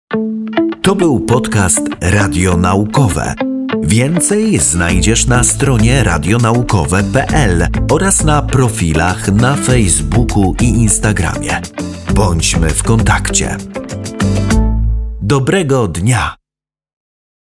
Male 30-50 lat
Voice artist with a young, charismatic voice timbre.
Outro podcastu